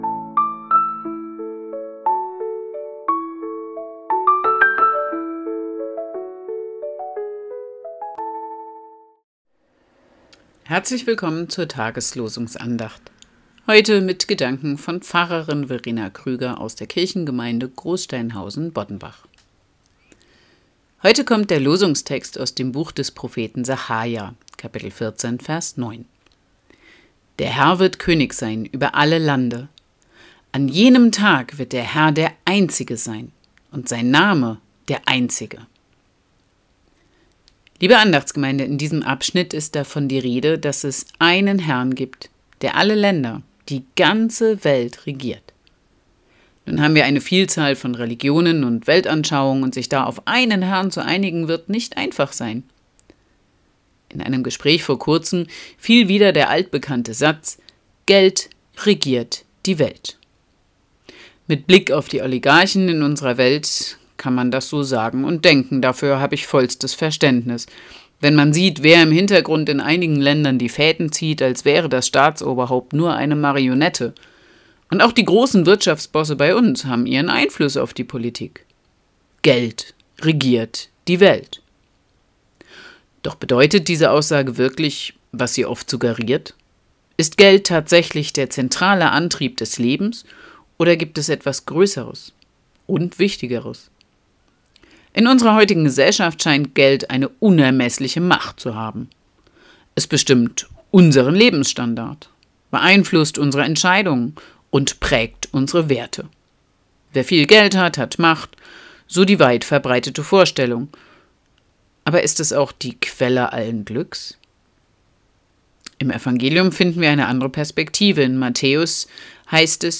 Losungsandacht für Freitag, 14.03.2025
Text und Sprecherin